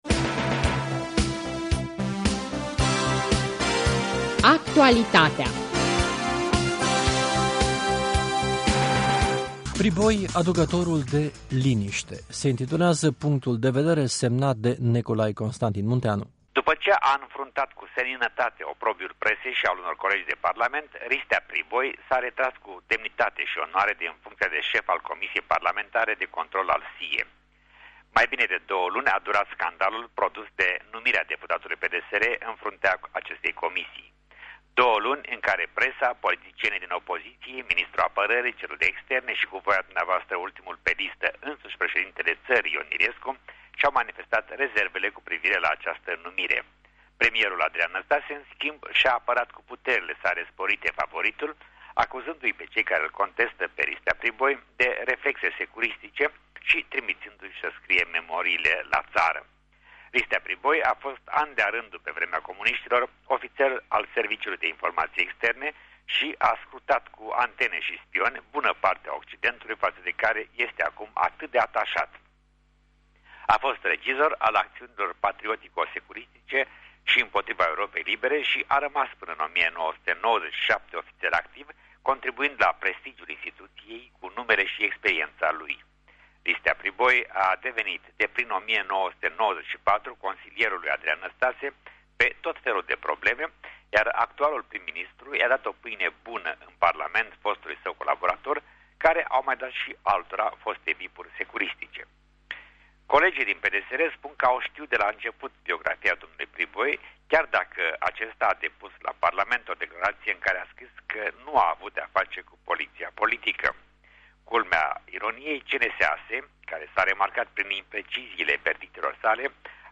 Actualitatea.